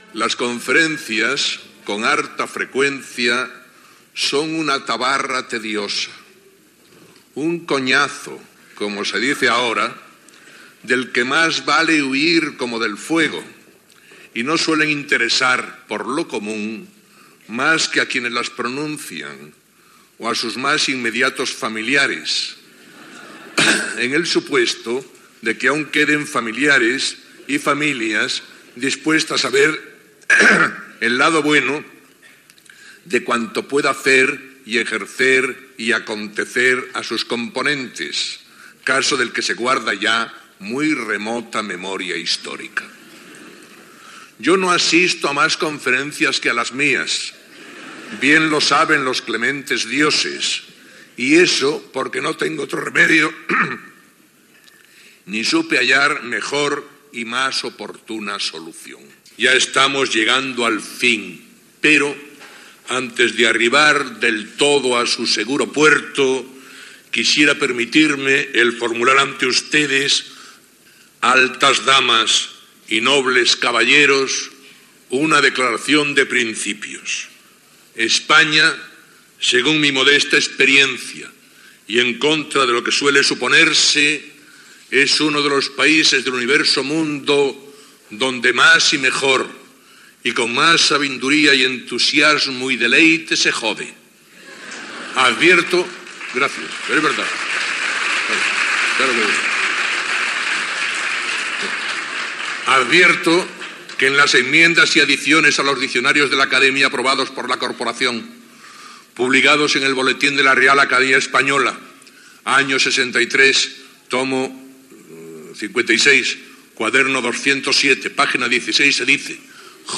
Conferència de l'escriptor Camilo José Cela a la Primera semana española del erotismo, celebrada a Madrid